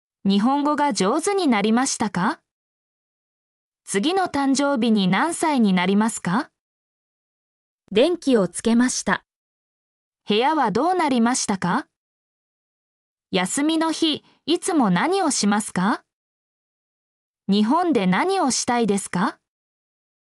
mp3-output-ttsfreedotcom-53_tj5akw6y.mp3